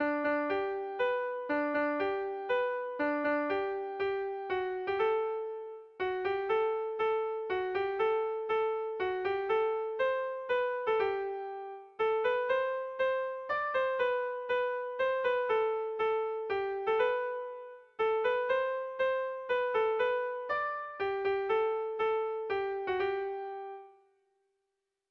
Bertso melodies - View details   To know more about this section
Kontakizunezkoa
Zortziko ertaina (hg) / Lau puntuko ertaina (ip)
ABDE